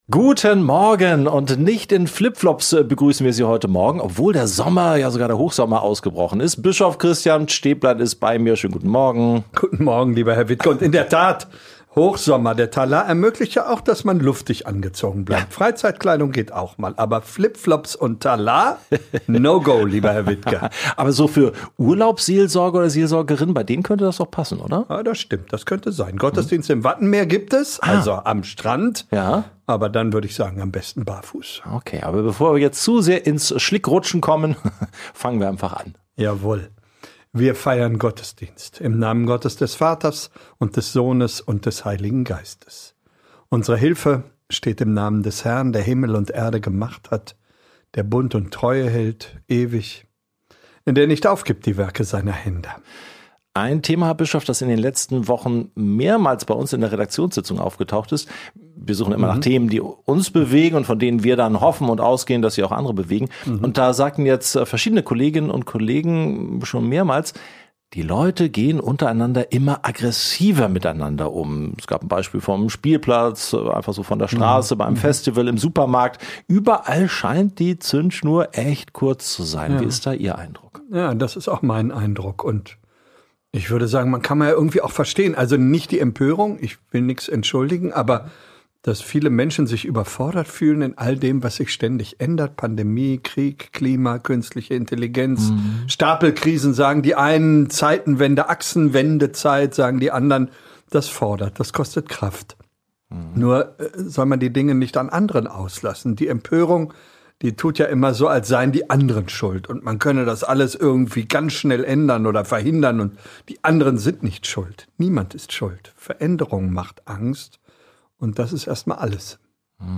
Verbunden mit dem Abschlussgottesdienst des Deutschen Evangelischen Kirchentages wird DIE Bibelstelle gelesen und diskutiert, die auch in Nürnberg Bibeltext am heutigen Sonntag ist. "Alles hat seine Zeit" - Tröstlich oder beliebig?